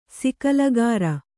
♪ sikalagāra